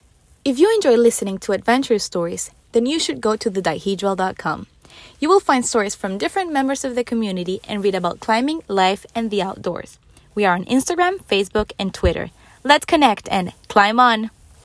Fabulous sounding voices, all of them.